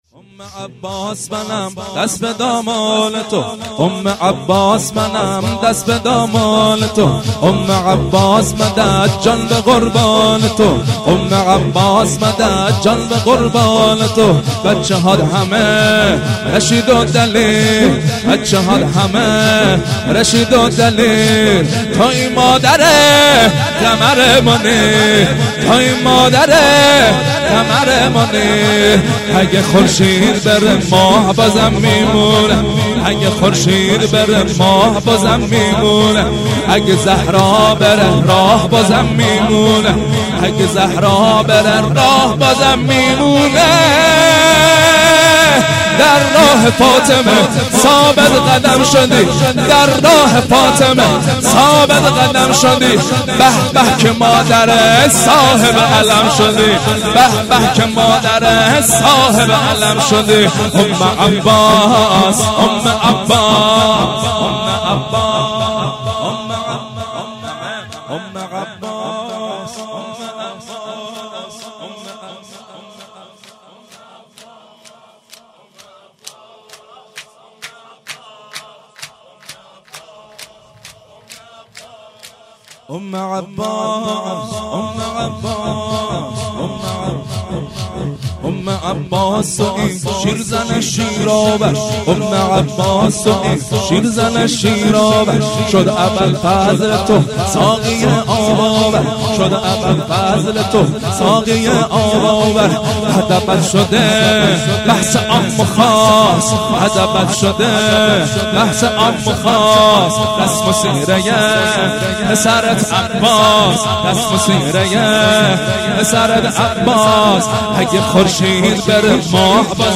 شور
هفتگی - وفات حضرت ام البنین س - جمعه 11 اسفند